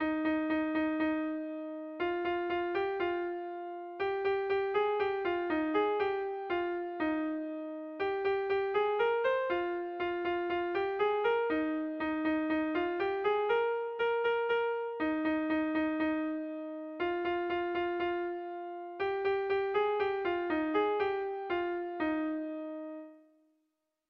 Doinu xarmanta.
Sei puntuko berezia
ABDEAB